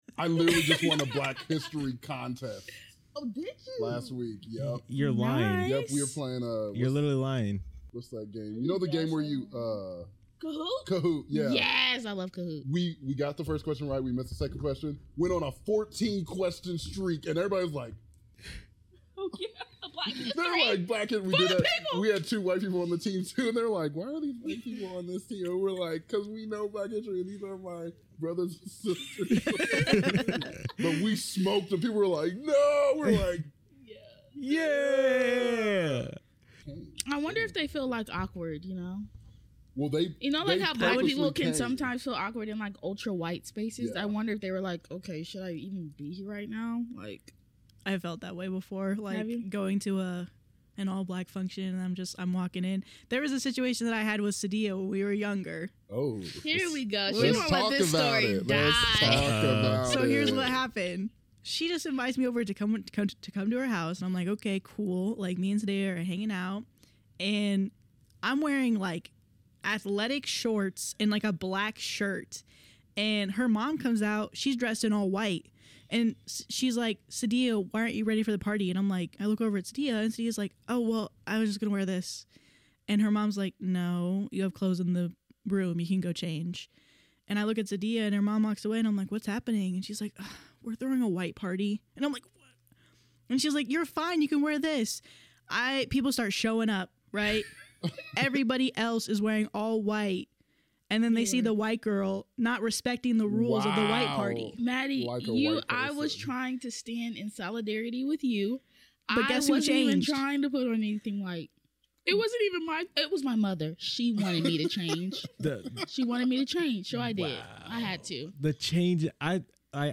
SORRY FOR THE ITALIAN ACCENTS